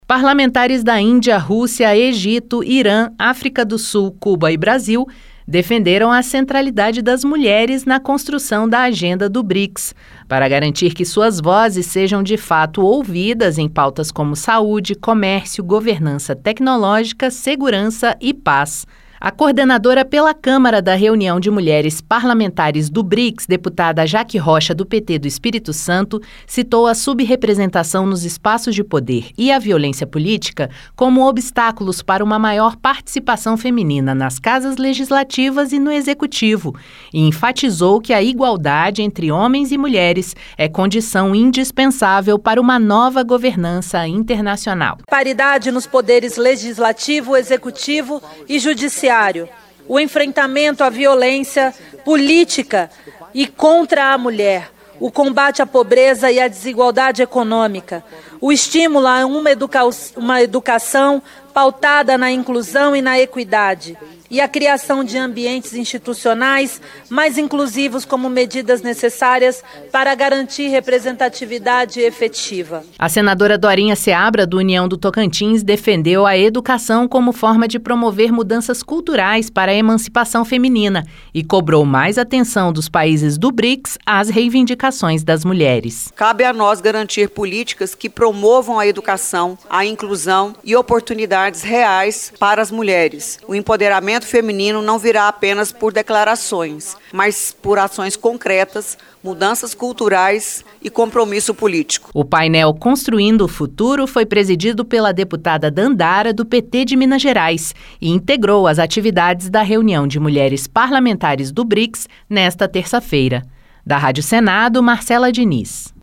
Parlamentares da Índia, Rússia, Egito, Irã, África do Sul, Cuba e Brasil defenderam a centralidade das mulheres na construção da agenda do Brics, em debate nesta terça-feira (3). Temas como paridade no Legislativo e violência política de gênero foram abordados na audiência que integrou a programação da reunião de mulheres parlamentares do Brics. A senadora Dorinha Seabra (União-TO) defendeu a educação como forma de promover mudanças culturais para a emancipação feminina e cobrou mais atenção dos países do Brics às reinvidicações das mulheres.